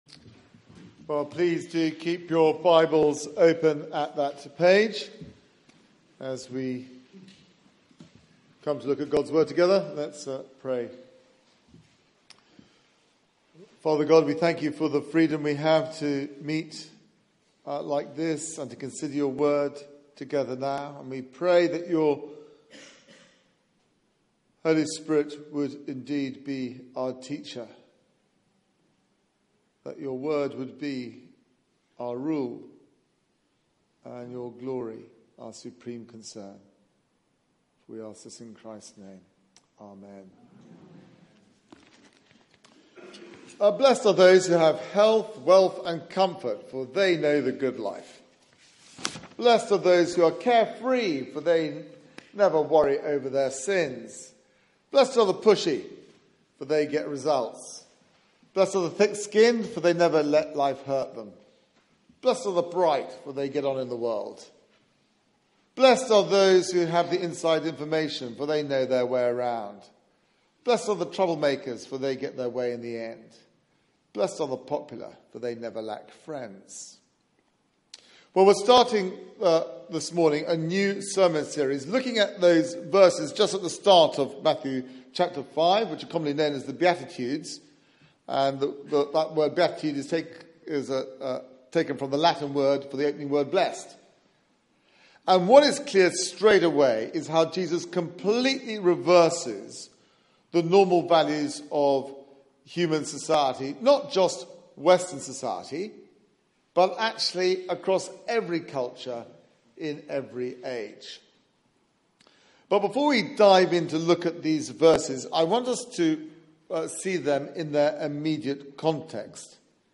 Media for 11am Service on Sun 09th Oct 2016 11:00
Series: The Beatitudes Theme: Blessed are the poor in spirit Sermon